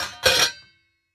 metal_lid_movement_impact_06.wav